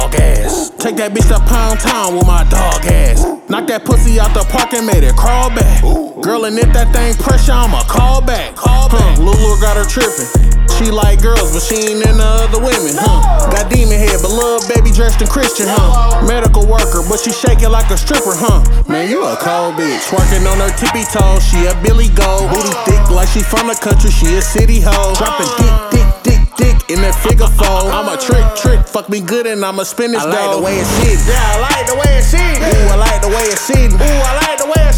Жанр: Хип-Хоп / Рэп
Hip-Hop, Rap